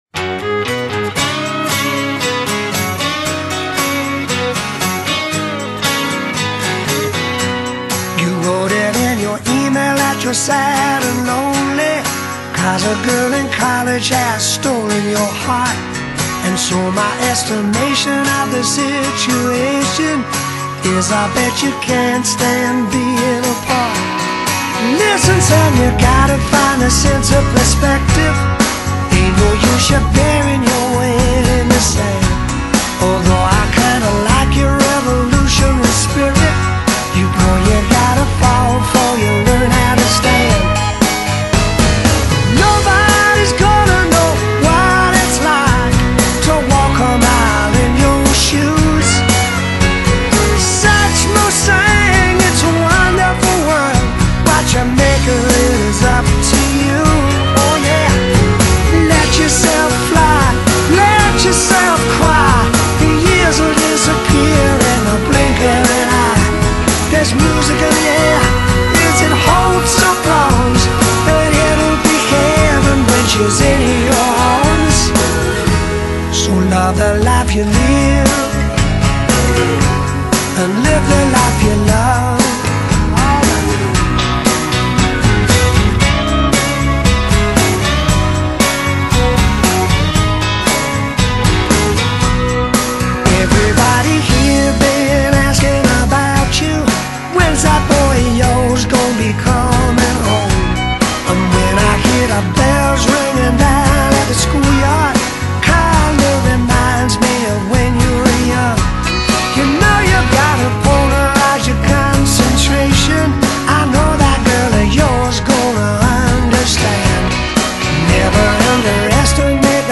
Genre: Rock, Pop Rock, Classic Rock